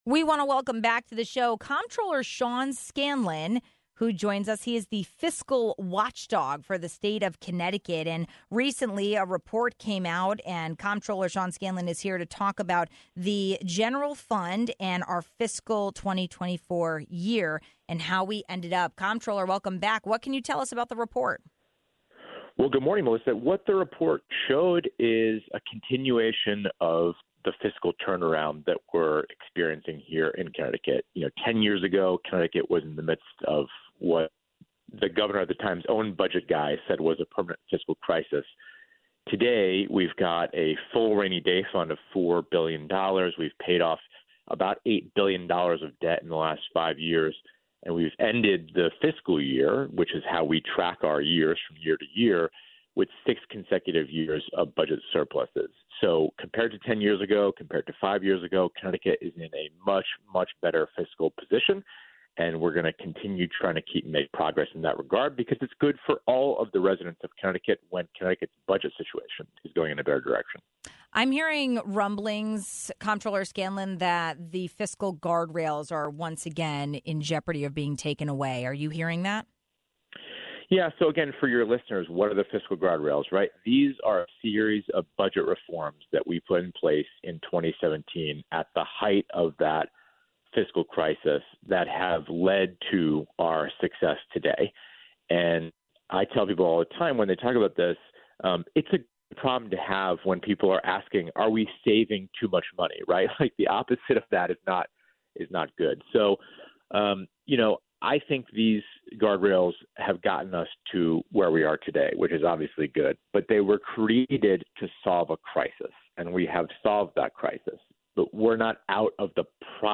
The state’s fiscal watchdog shared the 2024 fiscal financial report as well as his recent losses report for Connecticut. We also asked Comptroller Sean Scanlon about a recent roundtable discussion in Westport tackling child healthcare.